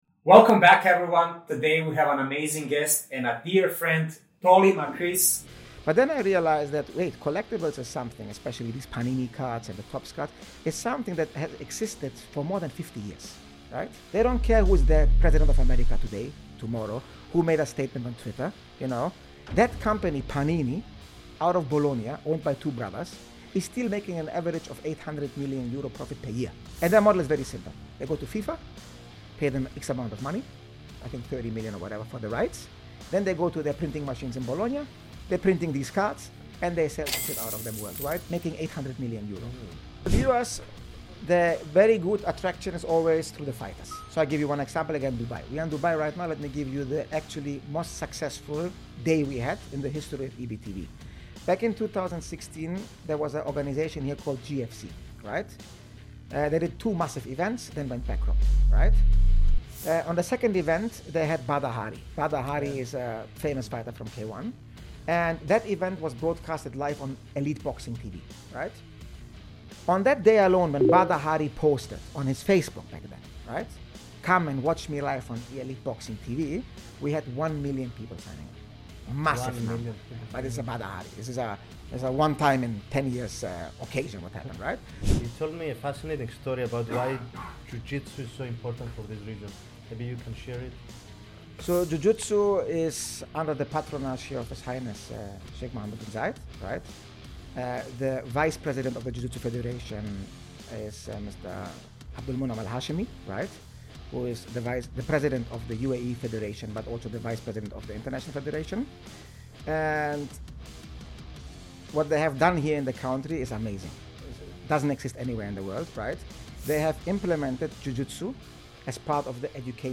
A sharp, trading-first conversation on liquidity, risk, and where derivatives go next.